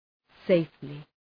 Shkrimi fonetik {‘seıflı}